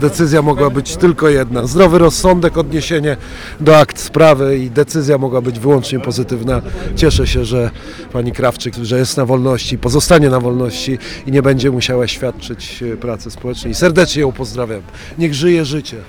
W drodze do świątyni nie chciał rozmawiać z mediami. Tylko Radiu Gdańsk udzielił krótkiego komentarza.